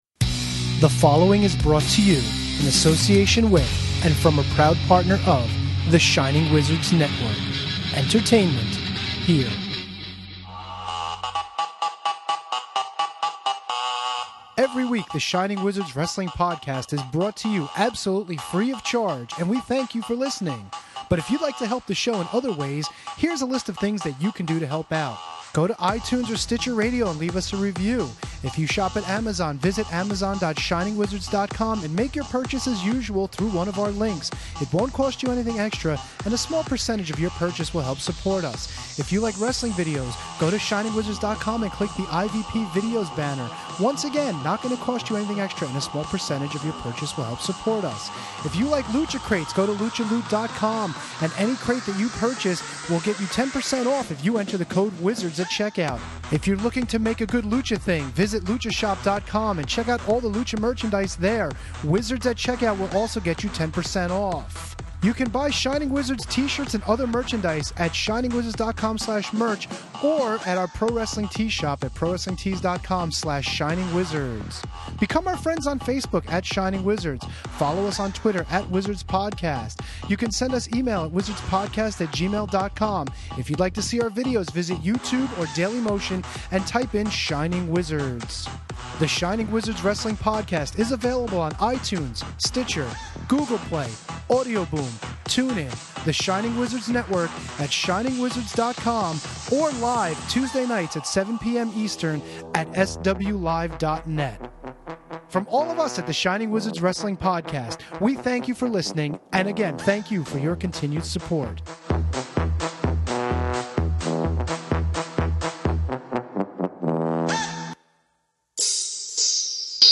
From Monroe, NJ, on October 15, 2016, the Wizards present the Legends of the Ring fan convention!